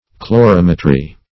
Chlorimetry \Chlo*rim"e*try\, n.
chlorimetry.mp3